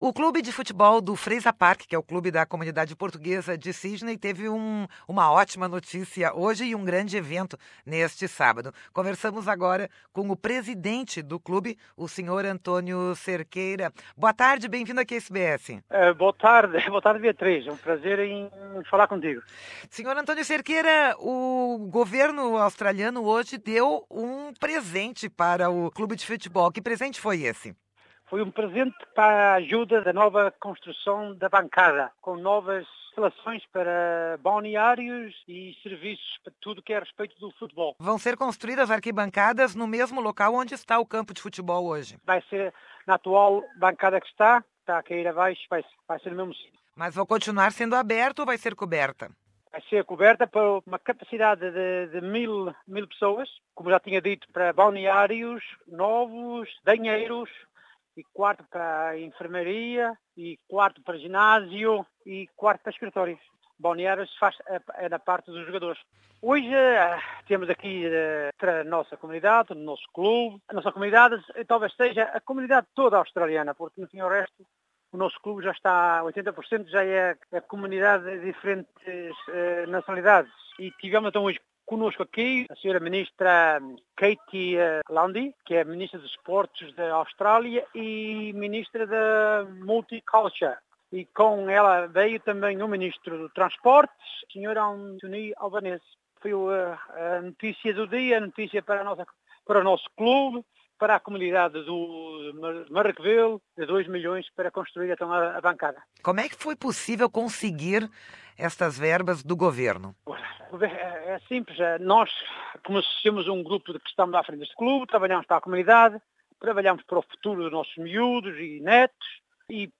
falou à SBS Portuguese após a cerimônia de entrega de 2 milhões de dólares ao clube de futebol da comunidade portuguesa de Sydney. A verba será usada, além da arquibancada coberta, também para construir vestiários, banheiros, academia de ginástica e escritórios.Os ministros federais do Desporto, Kate Lundy, e dos Transportes e Infraestrutura, Anthony Albanese, fizeram a entrega dos fundos no sábado, no clube, em Sydney.